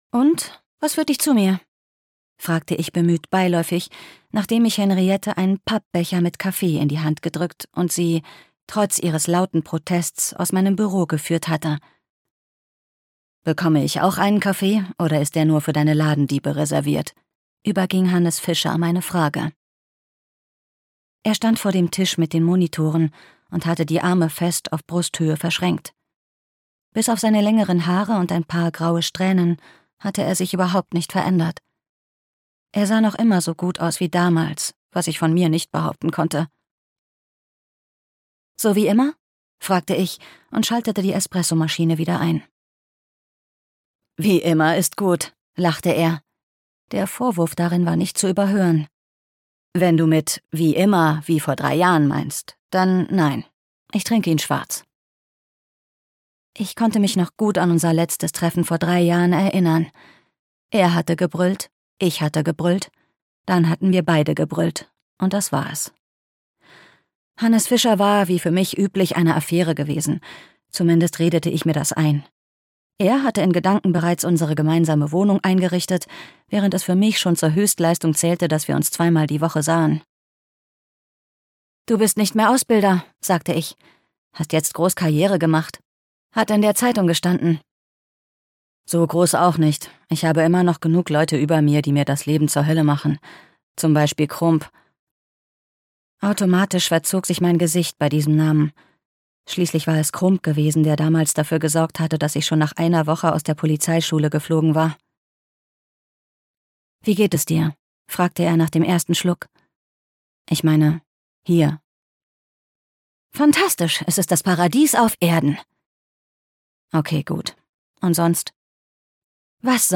Wiener Totenlieder - Theresa Prammer - Hörbuch